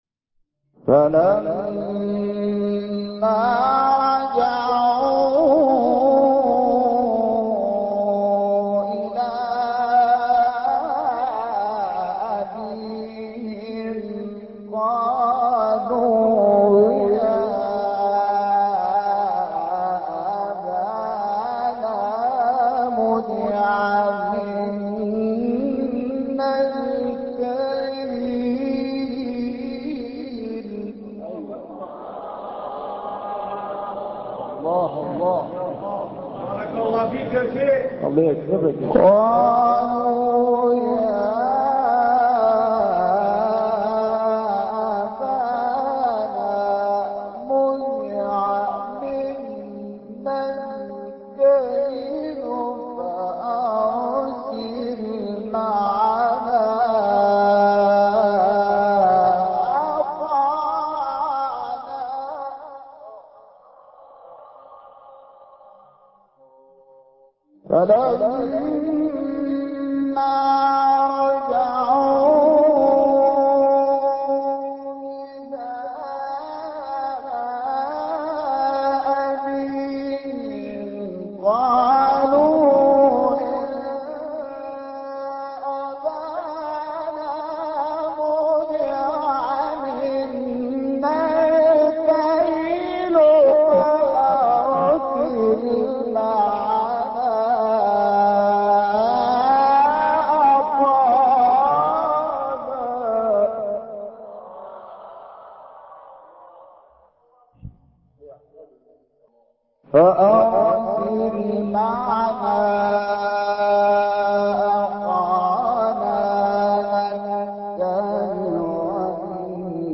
سورة يوسف ـ شحات انور ـ مقام البيات - لحفظ الملف في مجلد خاص اضغط بالزر الأيمن هنا ثم اختر (حفظ الهدف باسم - Save Target As) واختر المكان المناسب